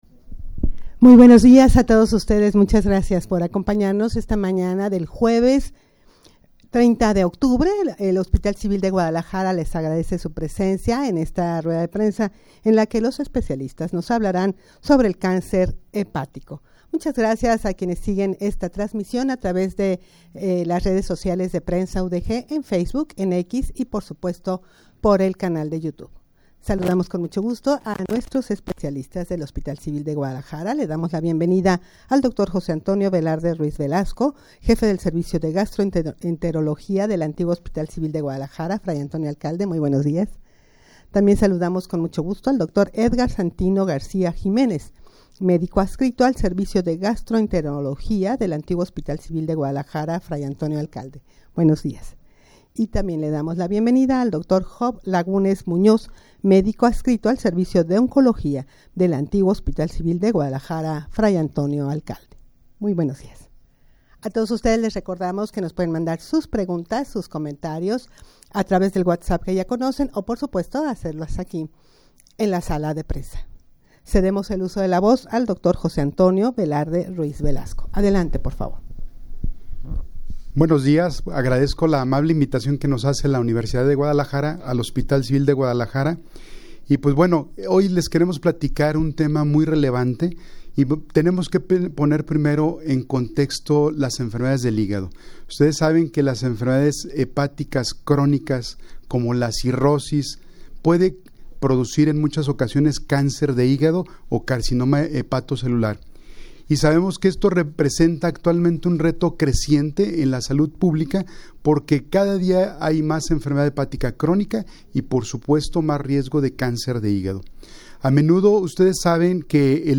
Audio de la Rueda de Prensa
rueda-de-prensa-sobre-cancer-hepatico.mp3